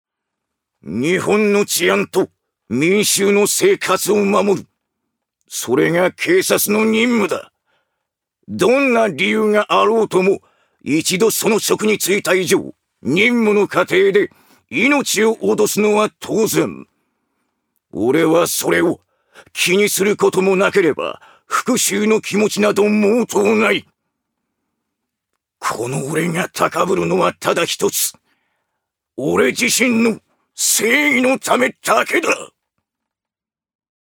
ジュニア：男性
セリフ２